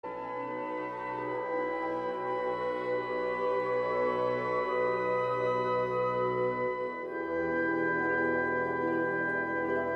Gattung: Suite für Blasorchester
Besetzung: Blasorchester